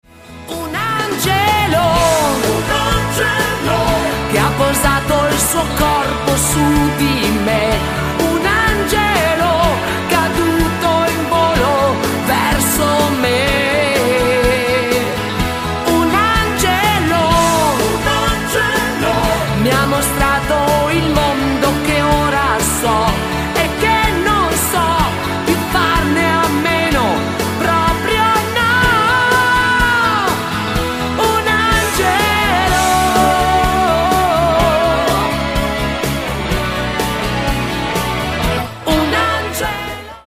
MODERATO  (4.01)